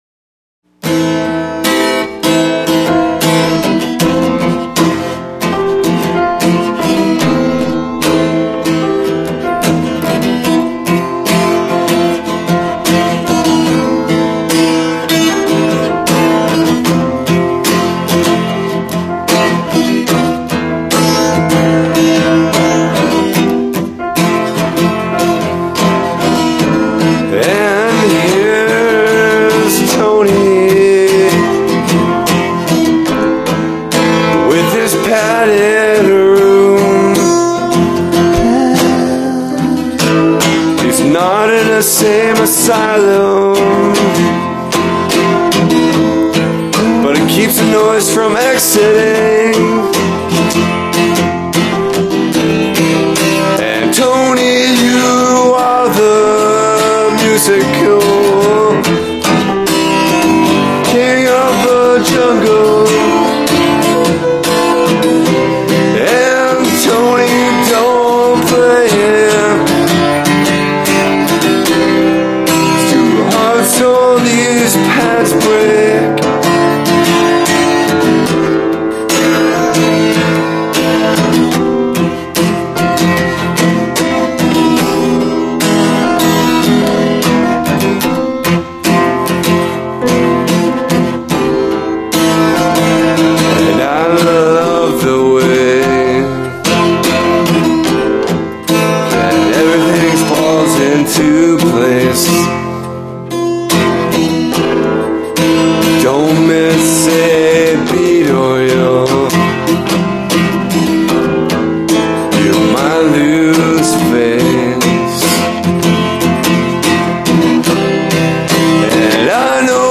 Acoustic
Acoustic Guitar
Main Vocals
Piano
Backup Vocals